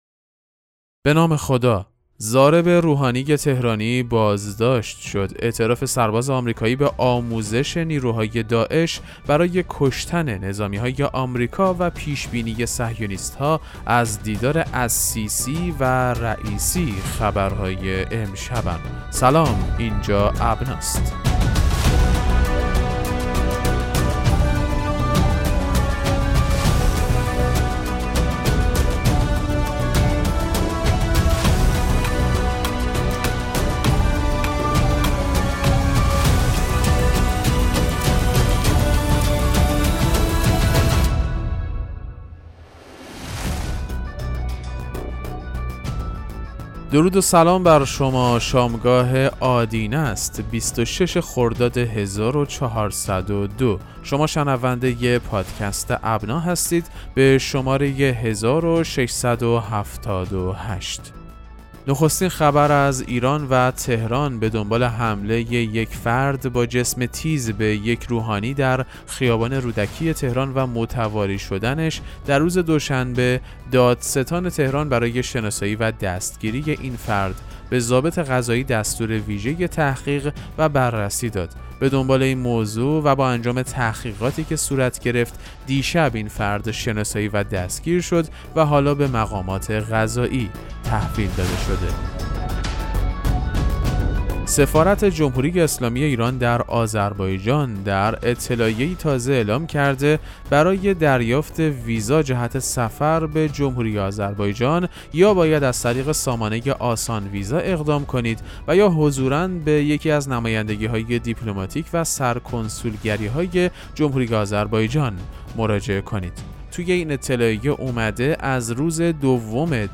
پادکست مهم‌ترین اخبار ابنا فارسی ــ 26 خرداد 1402